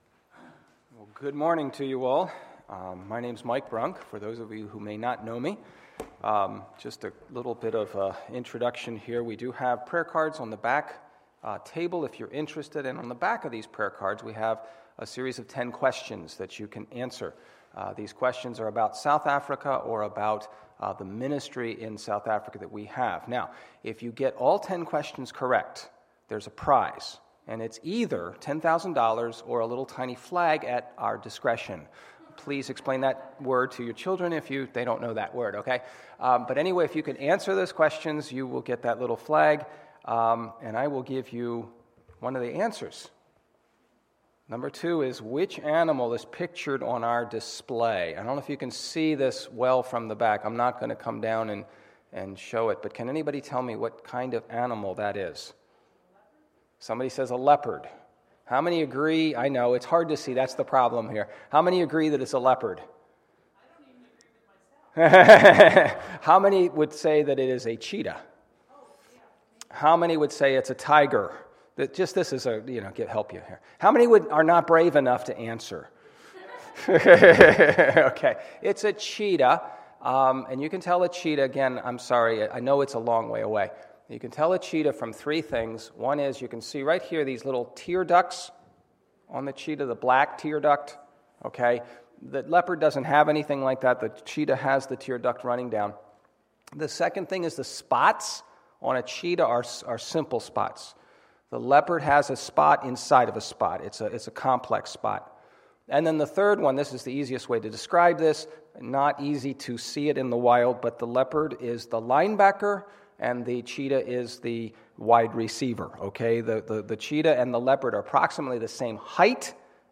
Sunday, September 27, 2015 – Missions Conference Sunday School Service